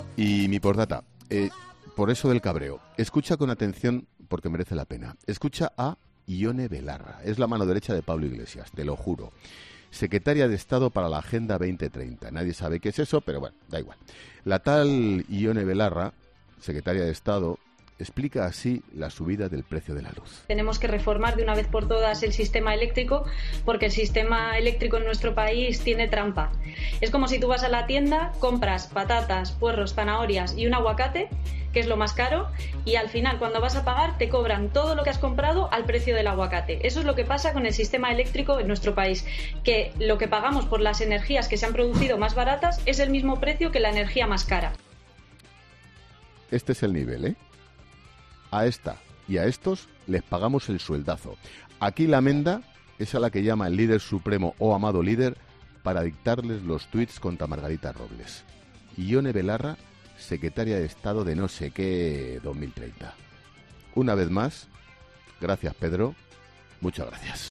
La tal Ione Belarra, secretaria de Estado explica así la subida del precio de la luz.